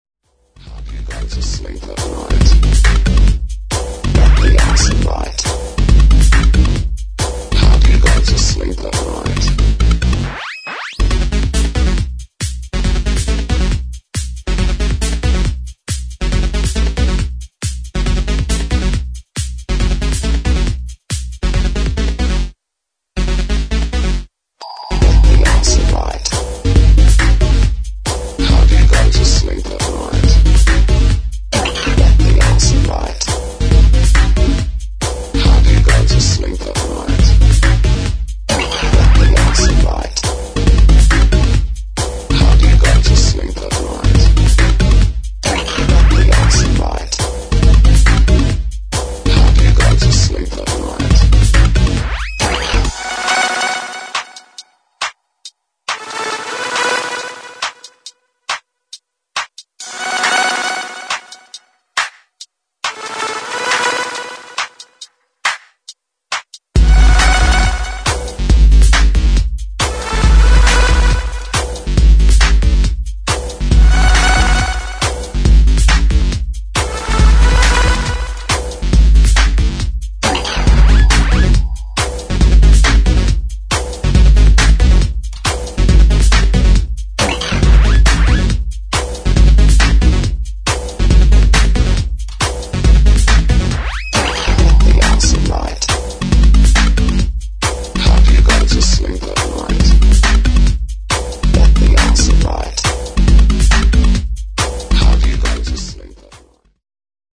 [ TECHNO / ELECTRO ]